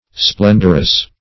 Splendrous \Splen"drous\, Splendorous \Splen"dor*ous\, a.